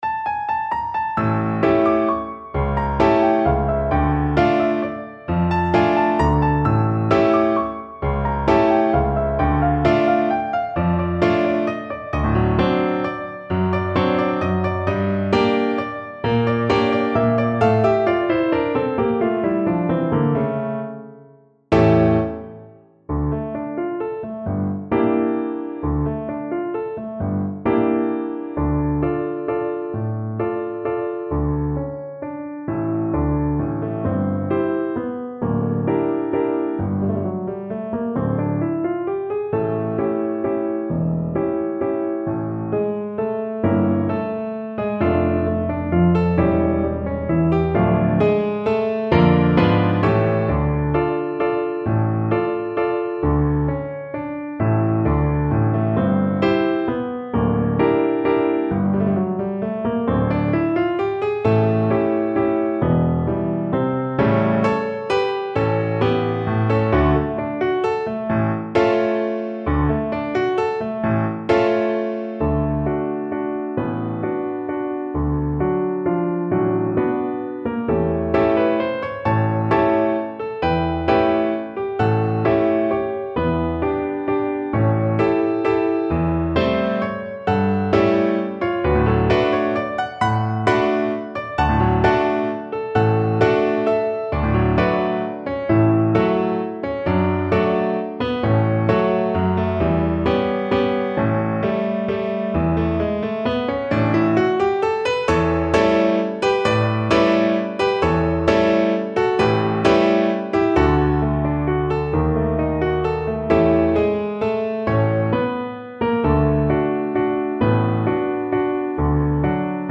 Allegro movido =180 (View more music marked Allegro)
3/4 (View more 3/4 Music)
Voice  (View more Easy Voice Music)
Classical (View more Classical Voice Music)
Mexican